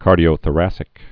(kärdē-ō-thə-răsĭk)